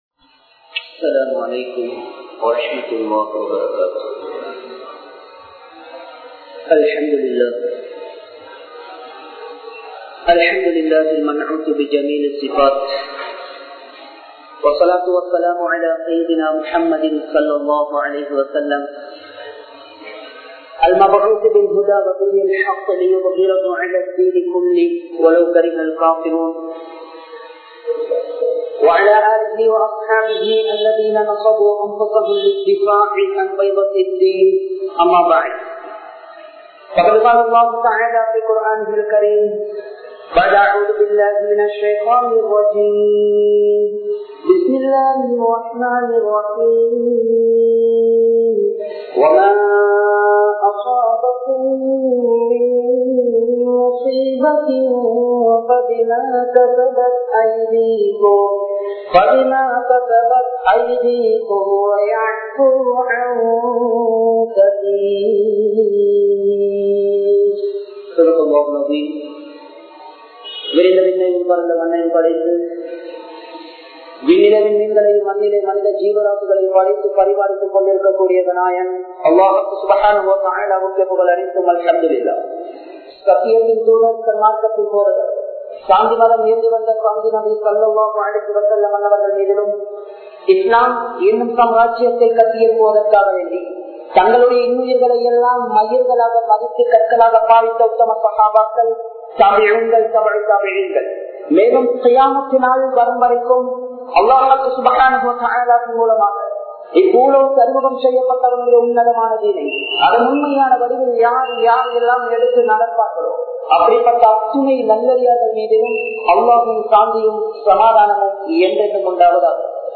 03Soathanaihalum 03Paavangalum (03சோதனைகளும் 03பாவங்களும்) | Audio Bayans | All Ceylon Muslim Youth Community | Addalaichenai
Muhiyadeen Jumua Masjith